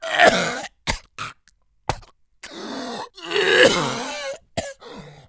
COUGH
1 channel
BONGCOFF.mp3